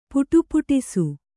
♪ puṭupu'isu